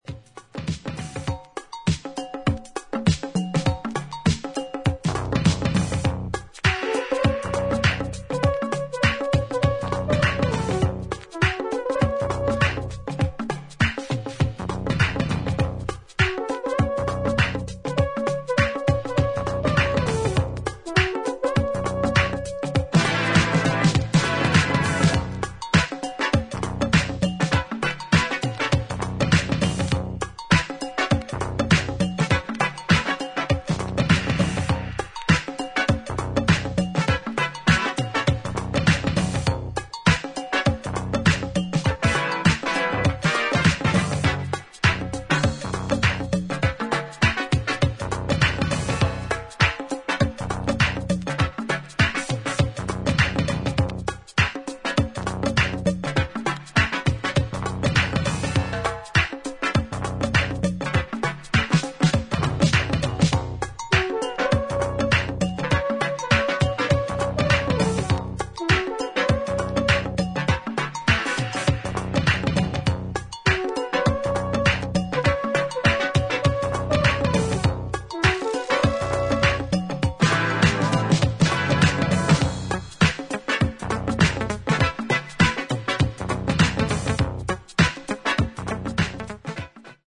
エレクトロニックなリズムにラテン・パーカッションが絶妙に絡む